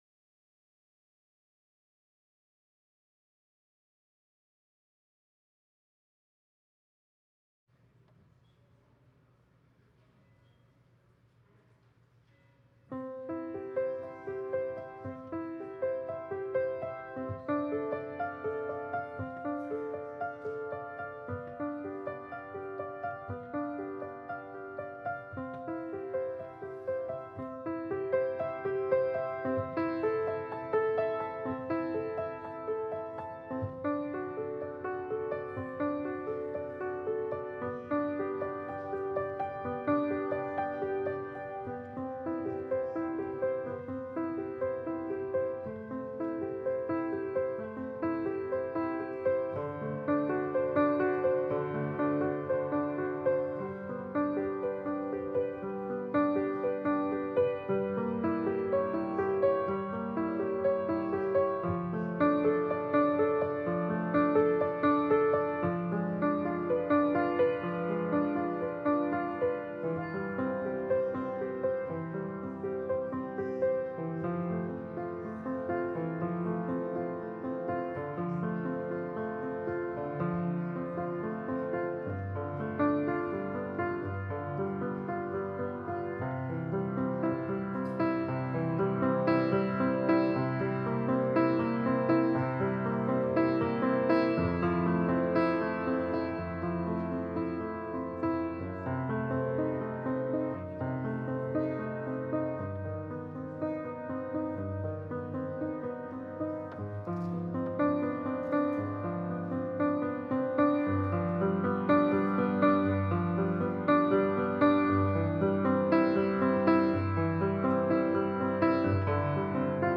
Passage: Matthew 5: 13-16 Service Type: Sunday Service Scriptures and sermon from St. John’s Presbyterian Church on Sunday